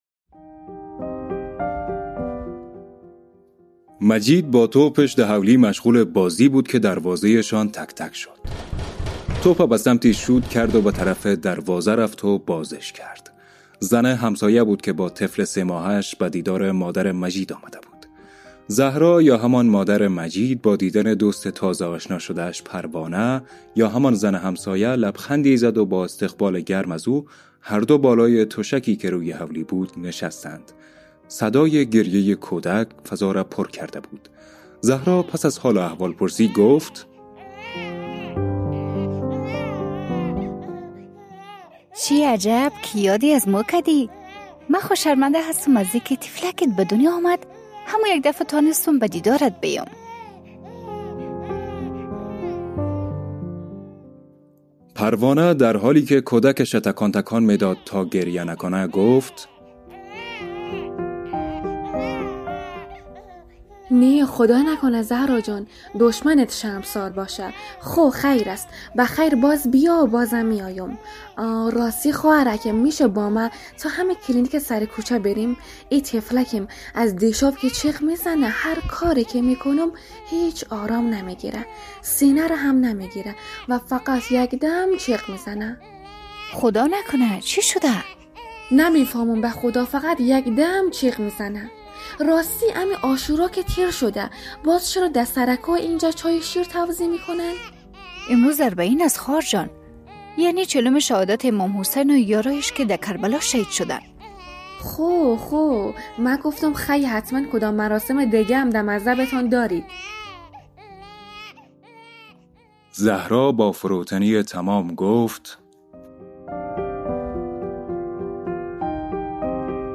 داستانک؛ خاک